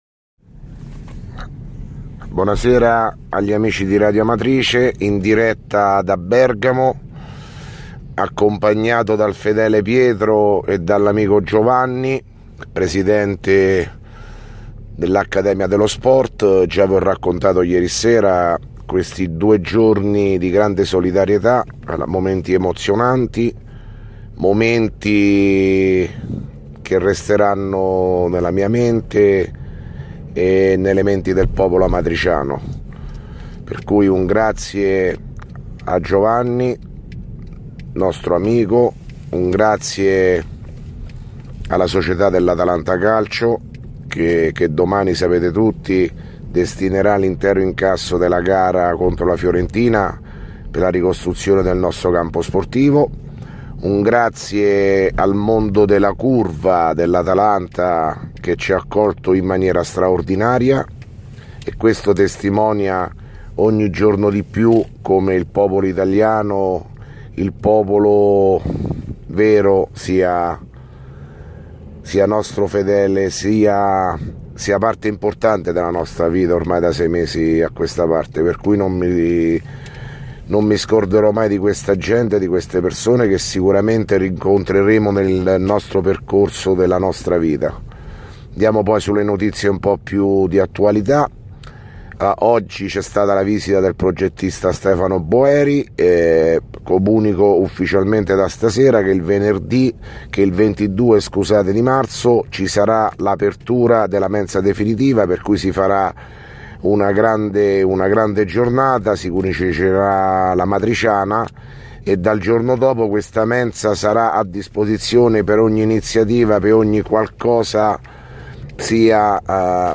RADIO AMATRICE: DA BERGAMO, IL MESSAGGIO DEL SINDACO PIROZZI (4 MARZO 2017) - Amatrice